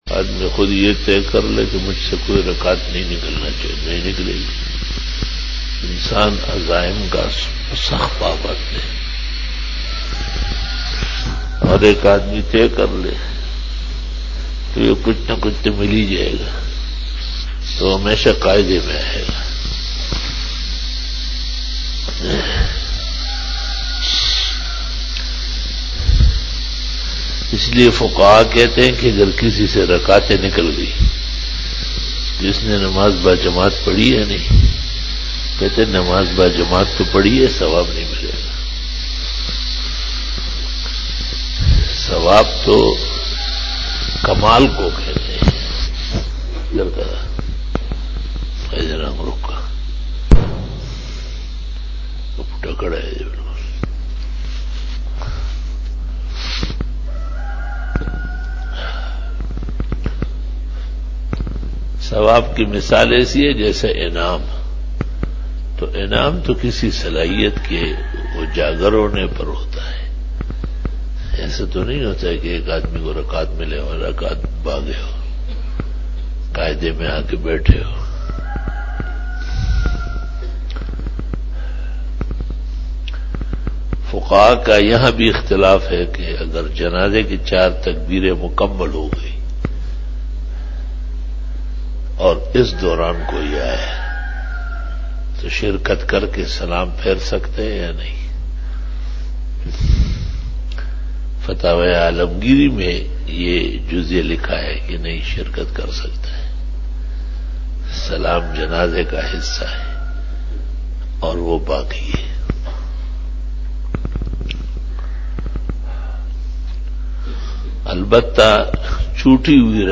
بیان بعد نماز فجر بروز ہفتہ15 جمادی الاول 1441ھ/ 11 جنوری 2020ء"